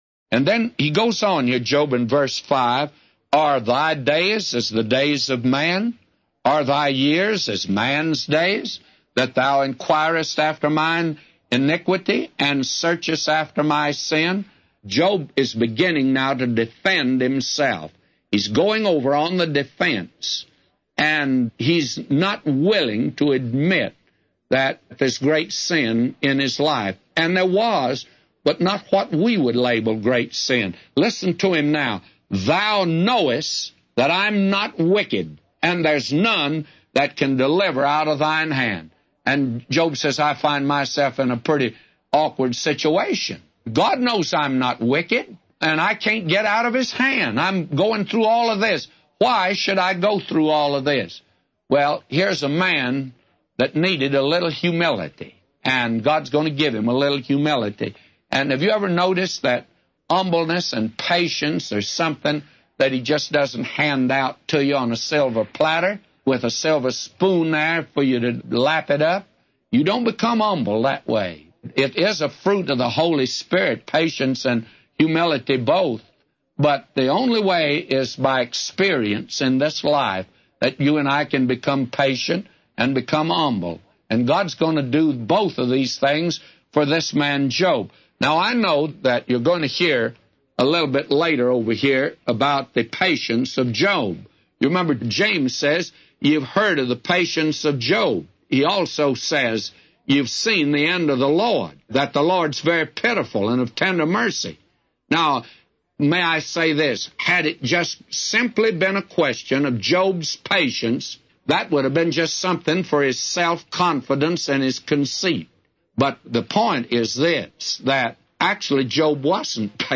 A Commentary By J Vernon MCgee For Job 10:5-999